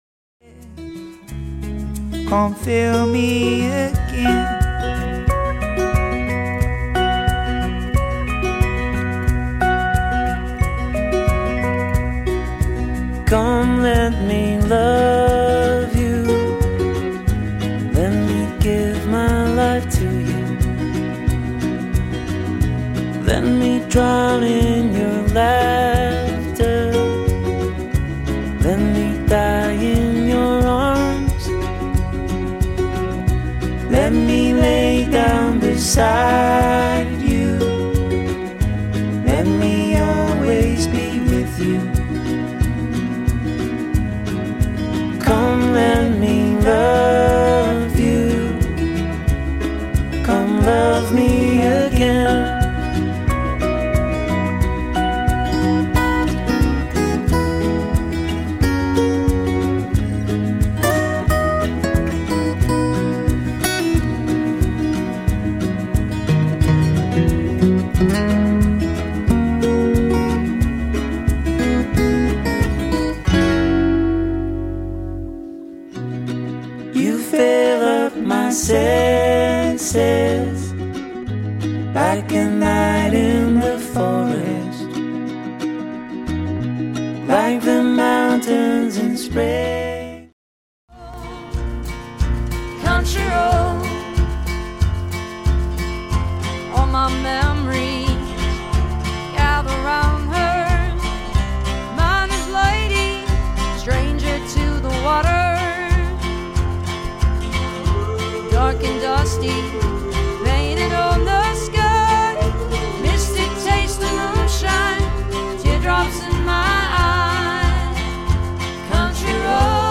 giving it a bluegrass touch.